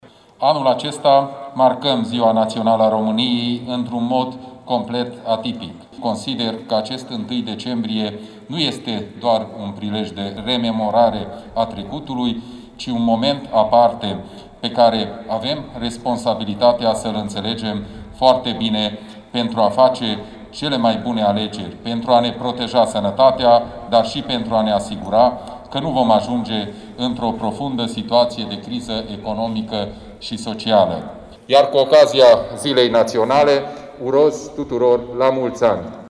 Ziua Națională, sărbătorită la Tg. Mureș
Președintele Consiliului Județean Mureș, Peter Ferenc, consideră că responsabilitatea individuală și colectivă ar trebui să primeze în această perioadă: